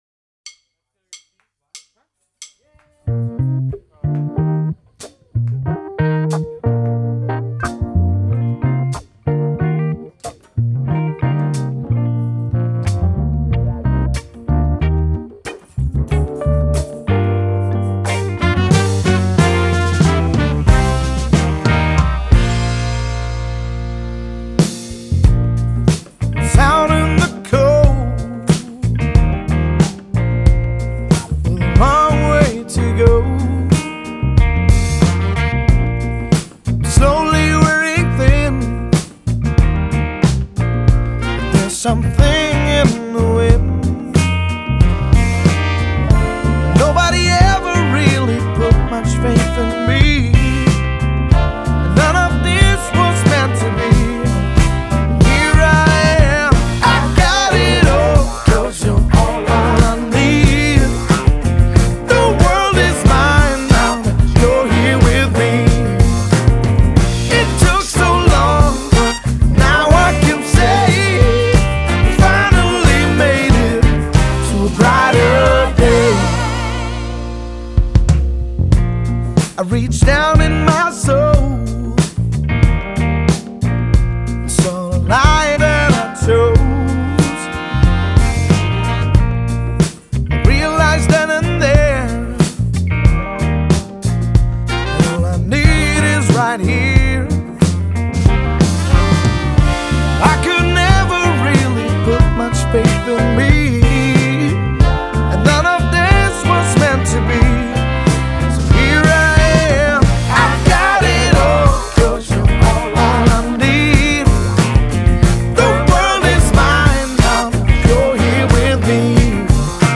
Genre: Pop, Rock, Blues